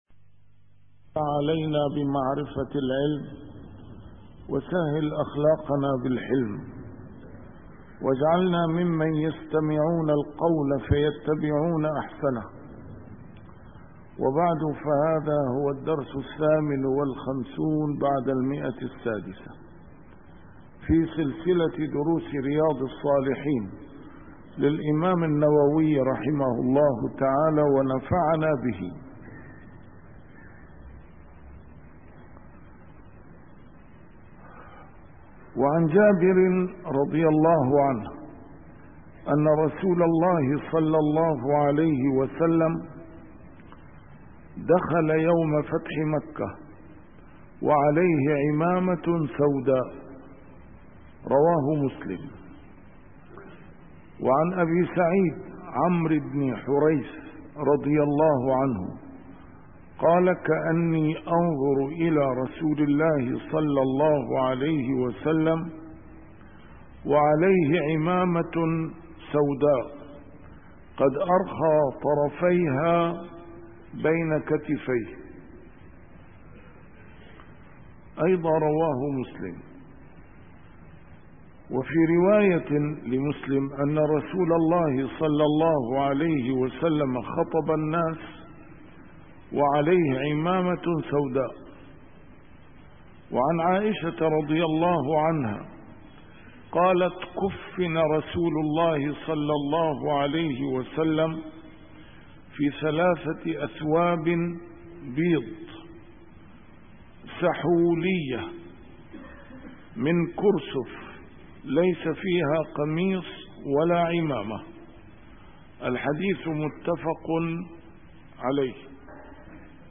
A MARTYR SCHOLAR: IMAM MUHAMMAD SAEED RAMADAN AL-BOUTI - الدروس العلمية - شرح كتاب رياض الصالحين - 658- شرح رياض الصالحين: استحباب الثوب الأبيض